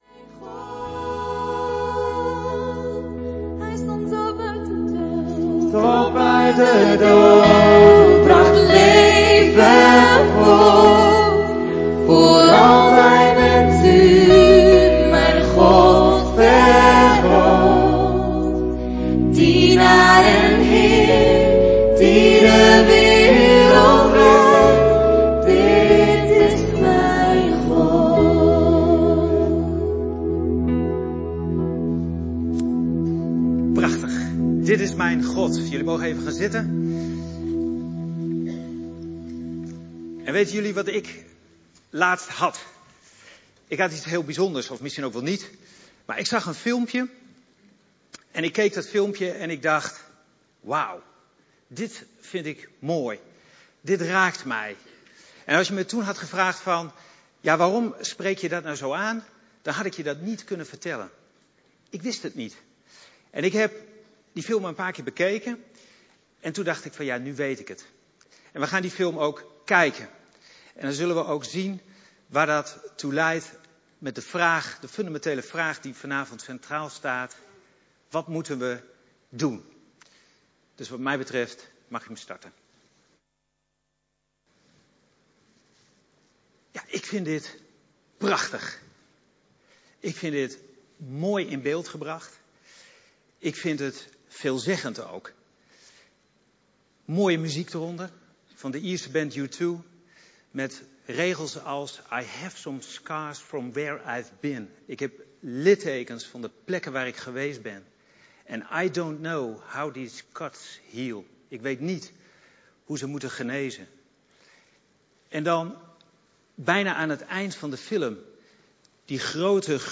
Doop- en toetrededienst – Wat moeten we doen?